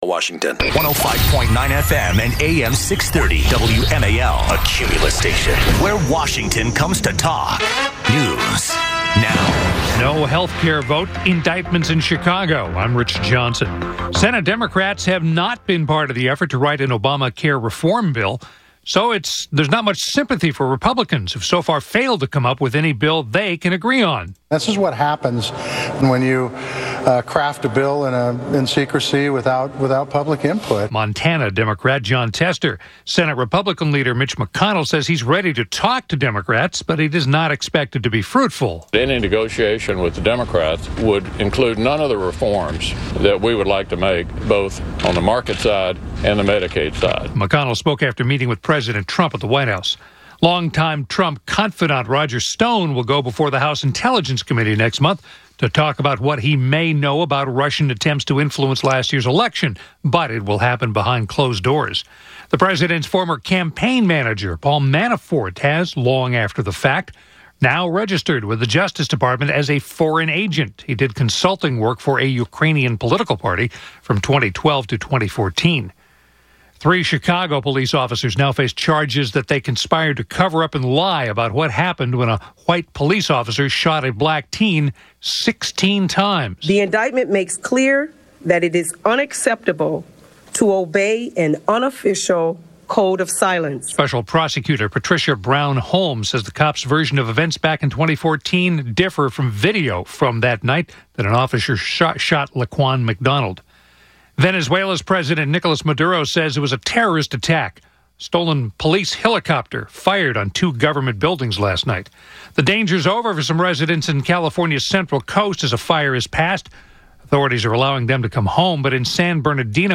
-CAL THOMAS - Syndicated Author and Columnist